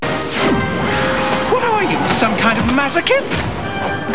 Spot Sound Bytes!
From Spider-Man: the Animated Series.